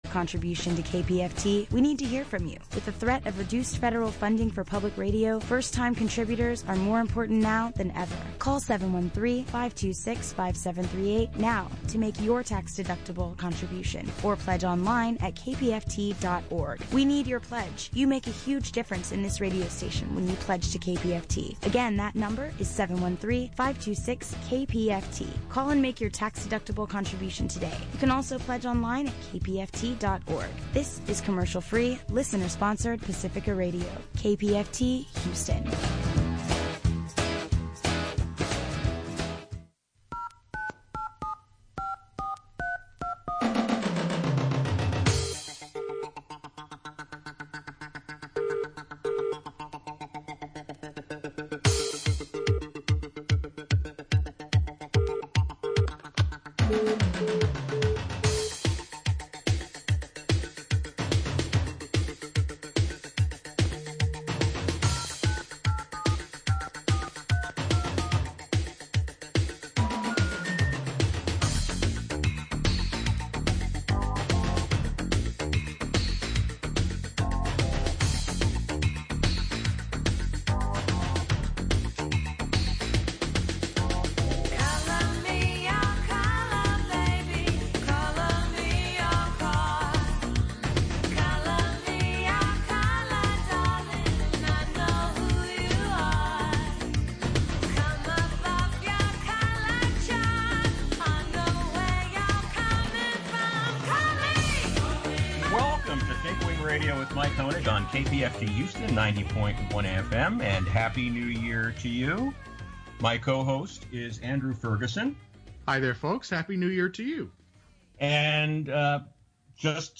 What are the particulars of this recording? This program was recorded early in the morning on SUNDAY, January 3. Due to Covid-19, shows are being prerecorded beginning March 13, 2020 and until further notice. We miss our live call-in participants, and look forward to a time we can once again go live.